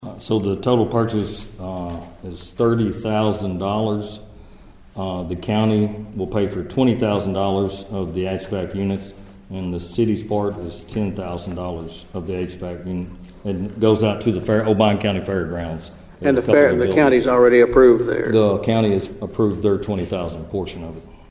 Also during the meeting, the City Manager asked Council members to approve funds for two HVAC units for the Obion County Fair Board.(AUDIO)